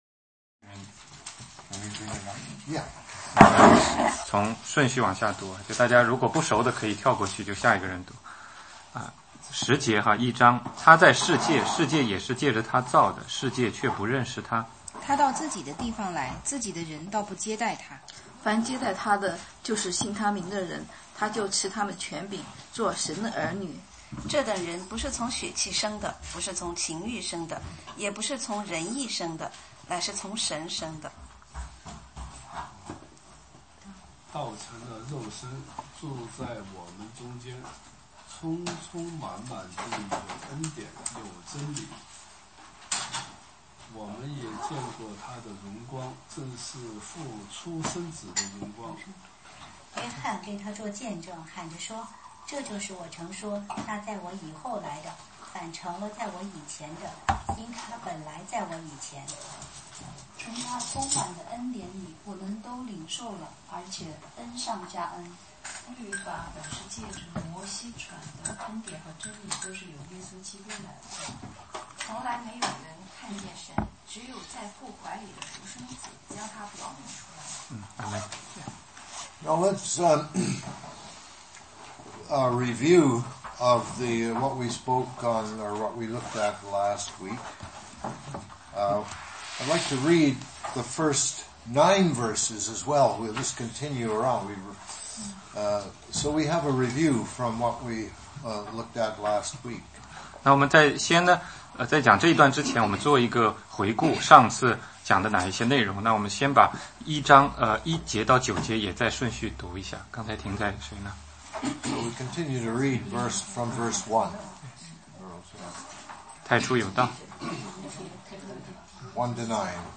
16街讲道录音 - 约翰福音1 1-13